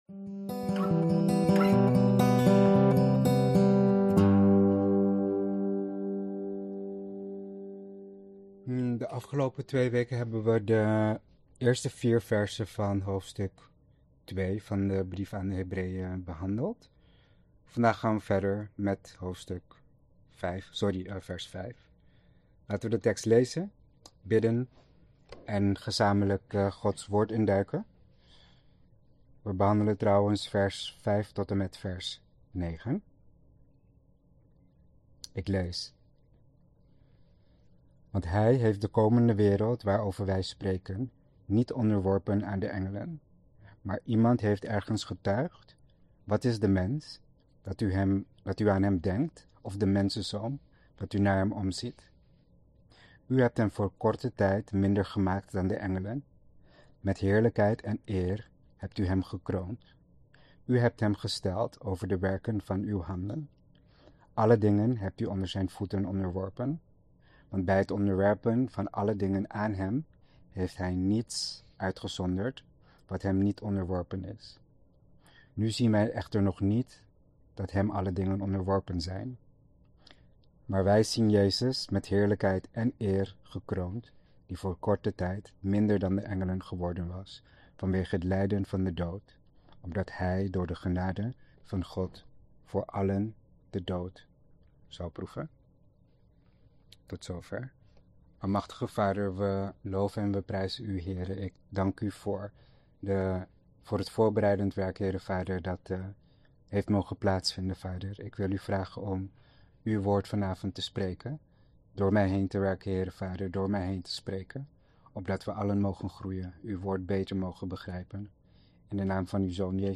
Verklarende prediking.